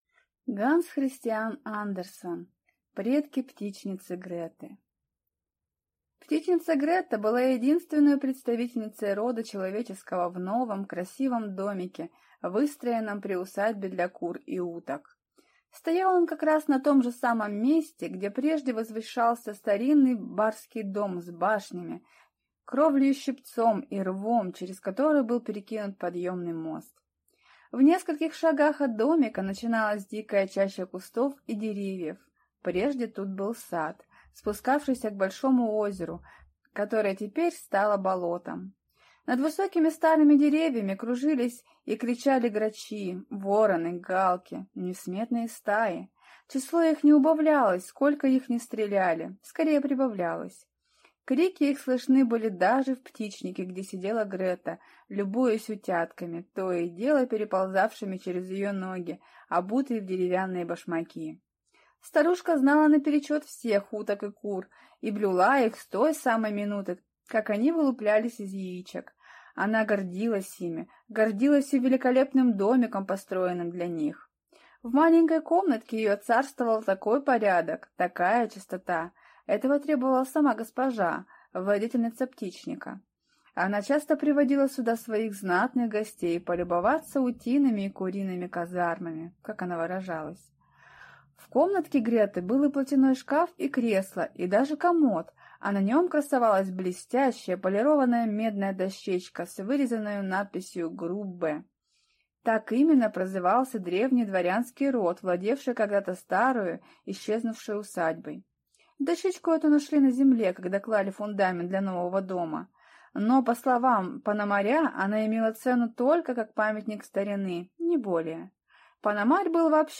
Аудиокнига Предки птичницы Греты | Библиотека аудиокниг